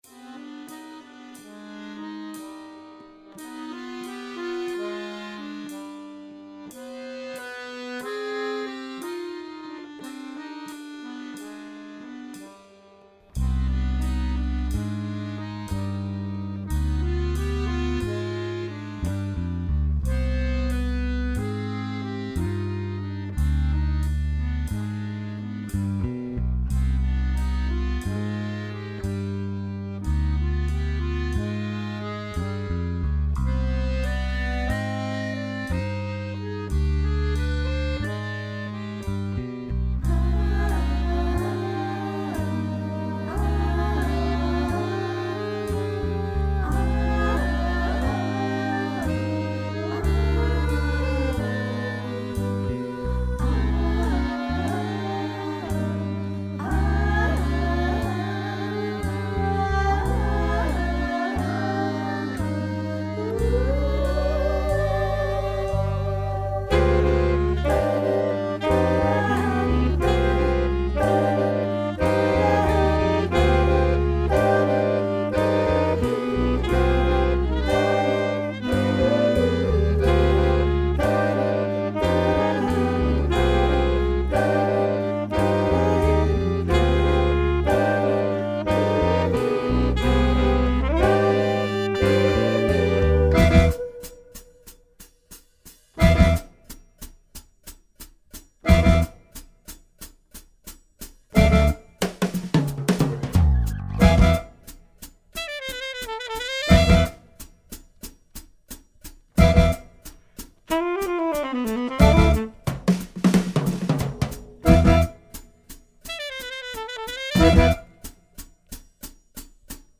Composing groove-based music for the accordion with varying degrees of improvisation - White Rose eTheses Online